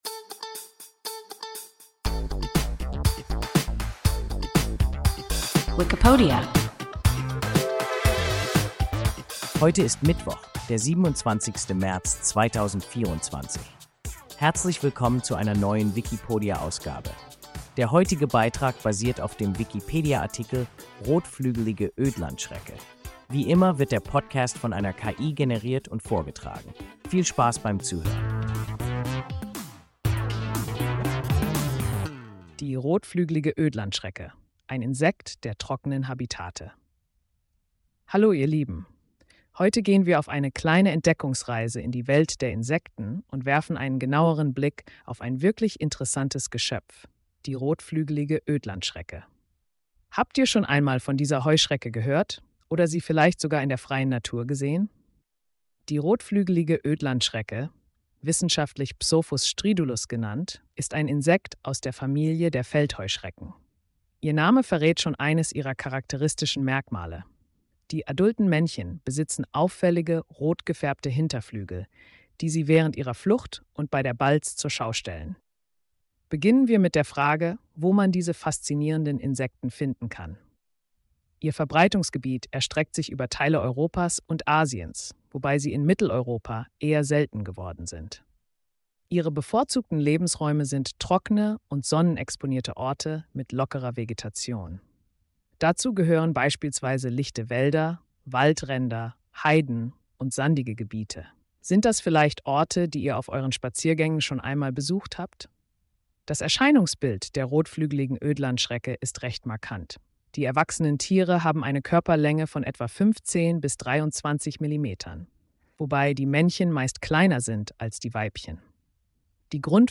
Rotflügelige Ödlandschrecke – WIKIPODIA – ein KI Podcast